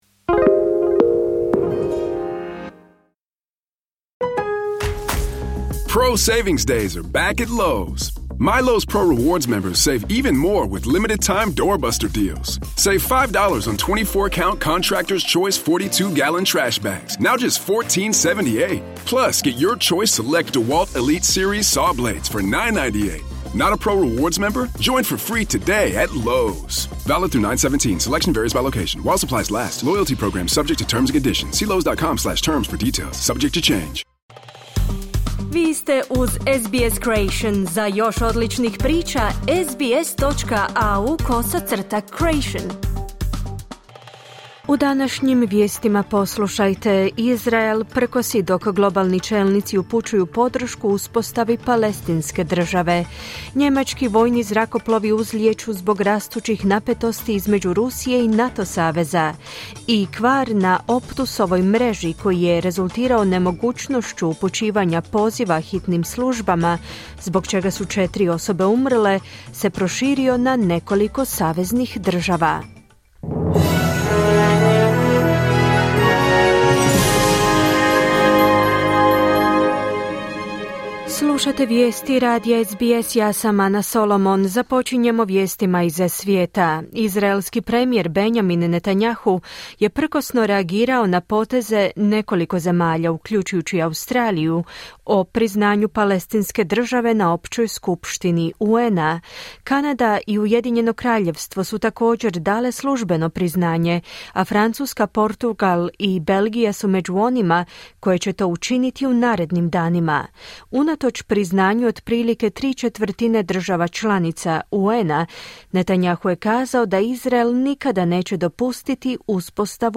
Vijesti, 22.9.2025.
Vijesti radija SBS na hrvatskom jeziku.